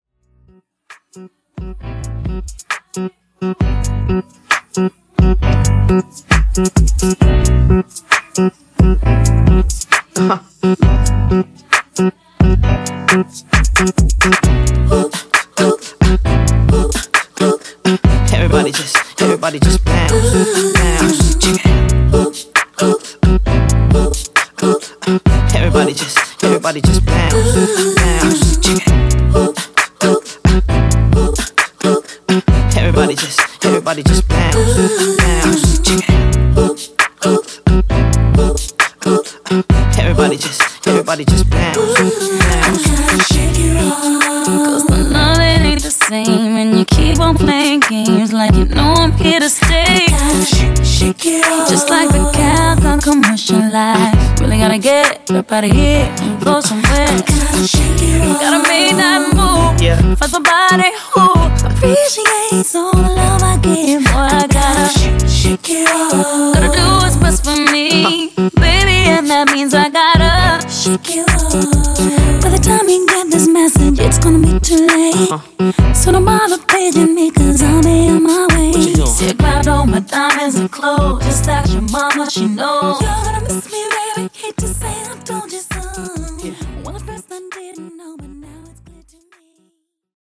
Clean Intro Edit)Date Added